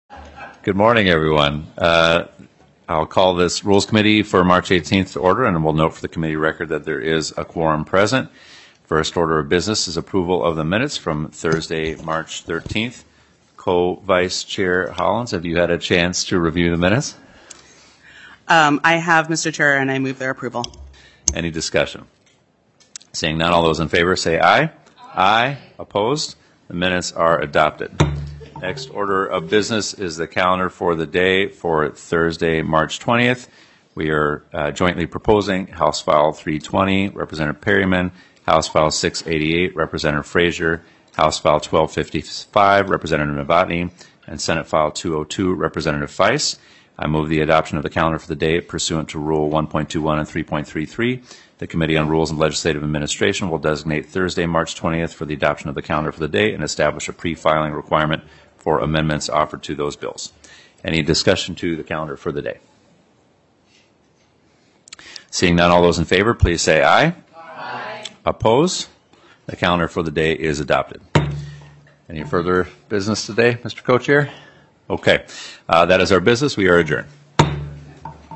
Representative Jamie Long, Co-Chair of the Committee on Rules and Legislative Administration, called the meeting to order at 10:02 am on Tuesday, March 18, 2025 in Capitol room G3.
Rep. Long holds the gavel for this hearing.